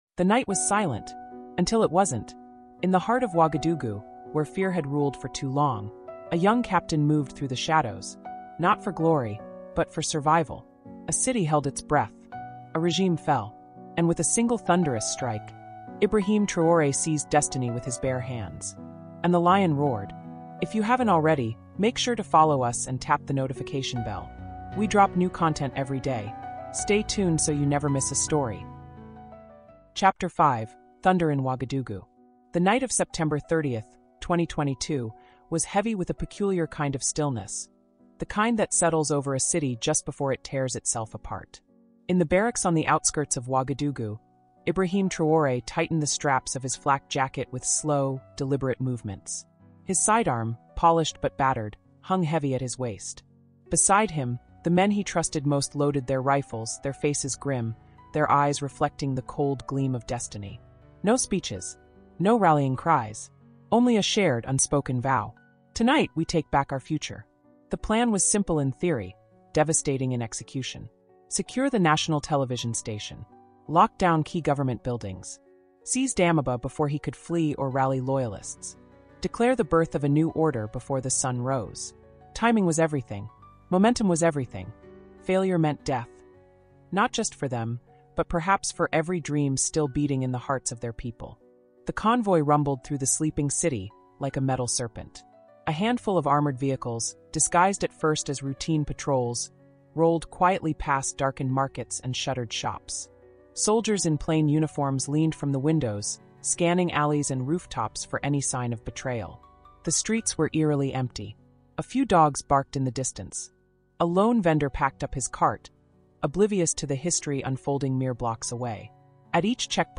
Captain Ibrahim Traoré: africa cultural diplomacy (ch5) | Audiobook